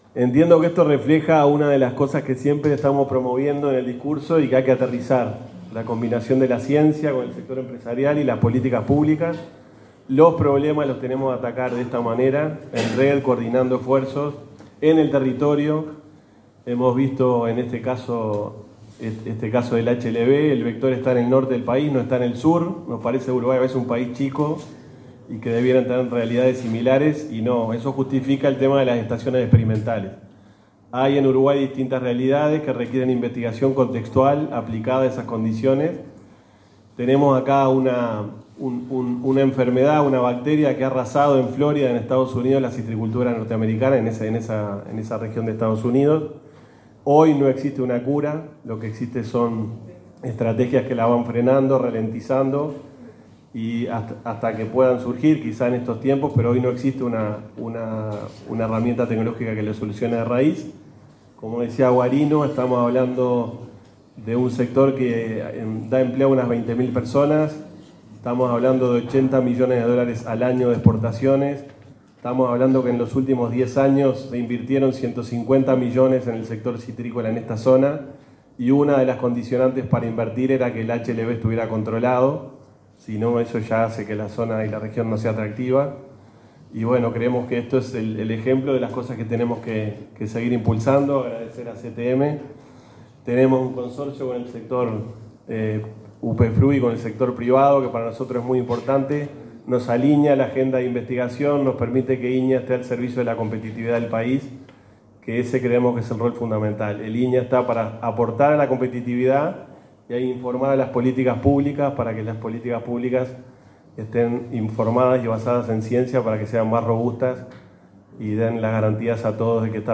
Palabras del presidente del INIA, Miguel Sierra 24/10/2025 Compartir Facebook X Copiar enlace WhatsApp LinkedIn El titular del Instituto Nacional de Investigación Agropecuaria (INIA), Miguel Sierra, hizo uso de la palabra tras la firma del convenio entre el organismo que preside y la Comisión Técnica Mixta de Salto Grande para desarrollar una campaña de concientización sobre una plaga que afecta los cítricos.